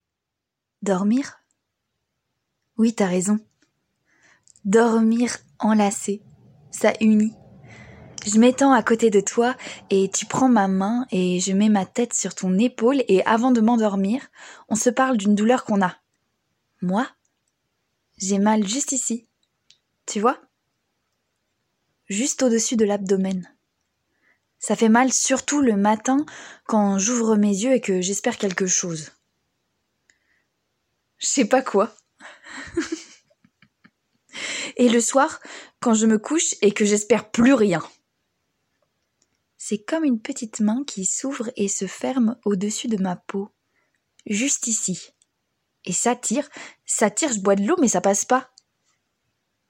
bande démo vocale
Voix off
- Soprano